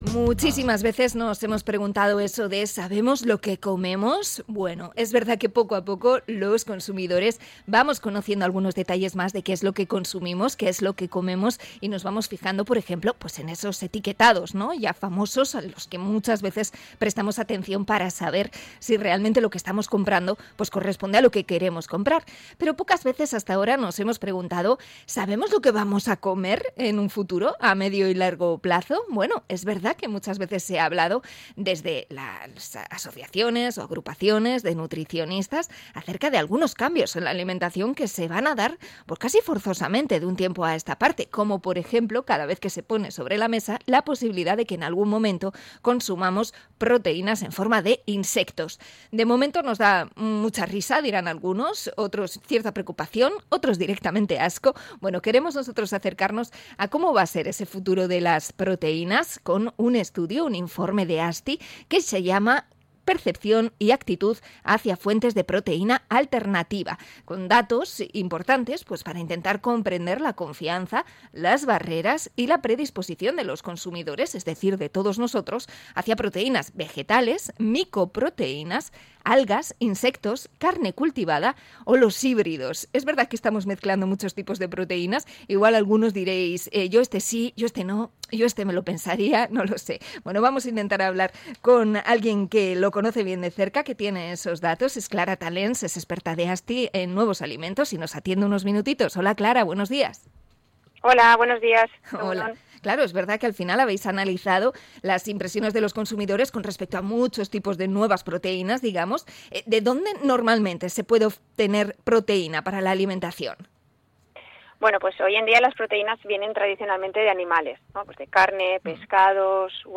Entrevista a experta en nuevos alimentos sobre la proteína